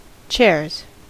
Ääntäminen
Ääntäminen US Tuntematon aksentti: IPA : /ˈtʃɛɹz/ IPA : /ˈtʃɛəz/ Haettu sana löytyi näillä lähdekielillä: englanti Käännöksiä ei löytynyt valitulle kohdekielelle.